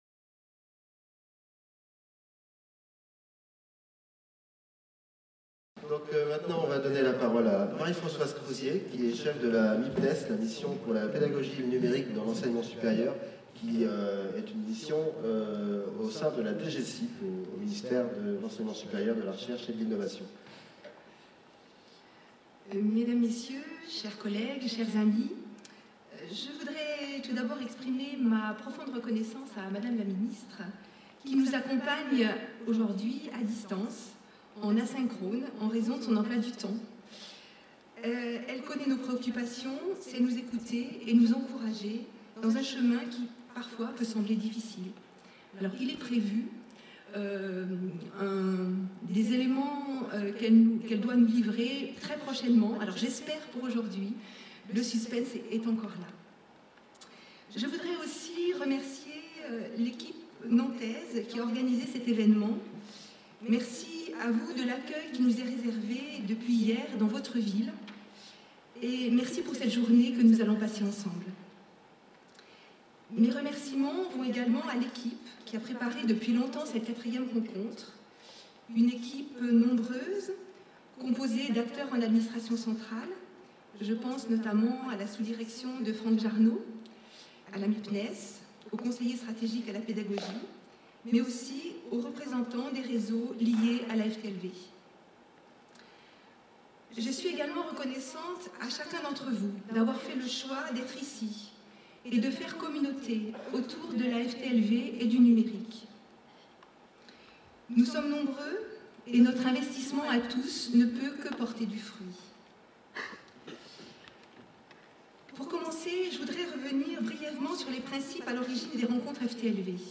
4ème rencontre : coorganisée le 5 décembre 2017, par la DGESIP et l'université de Nantes au Stéréolux (Ile de Nantes). Cycle de journées de rencontres sur les nouveaux modèles pour la F.T.L.V. 1er modèle : les effets du numérique sur l'organisation du travail, les réponses de l'enseignement supérieu.